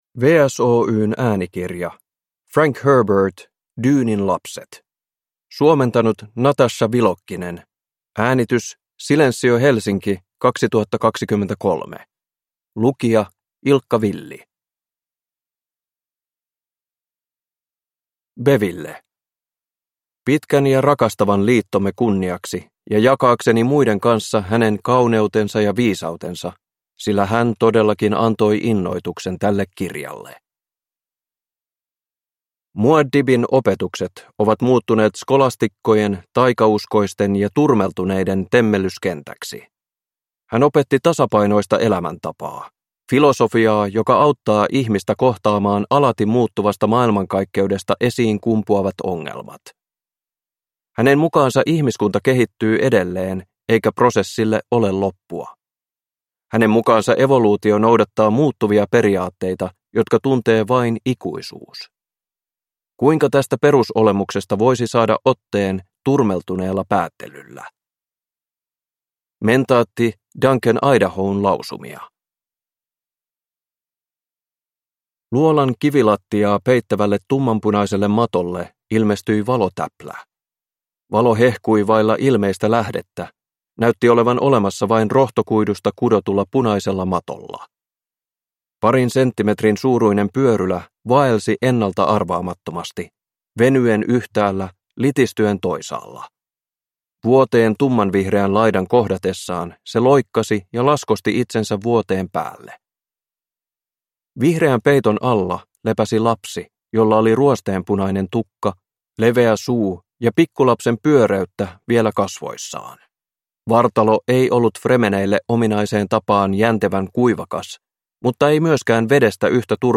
Dyynin lapset – Ljudbok